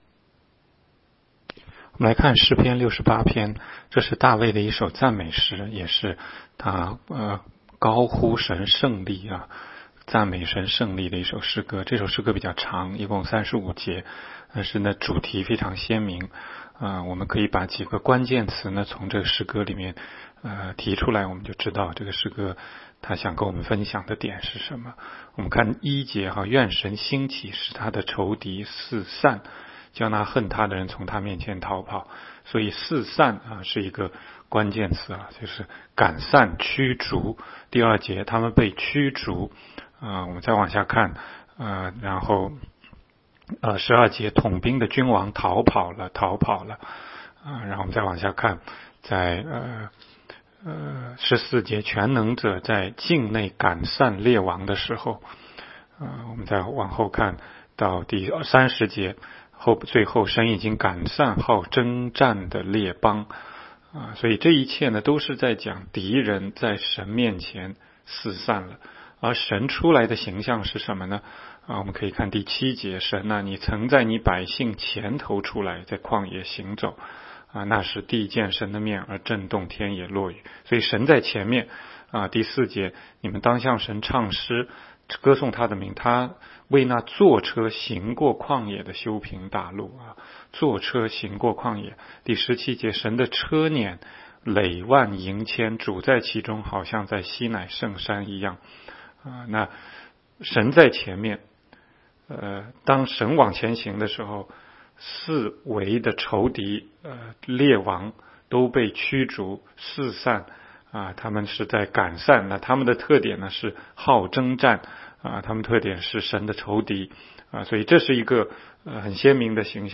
16街讲道录音 - 每日读经-《诗篇》68章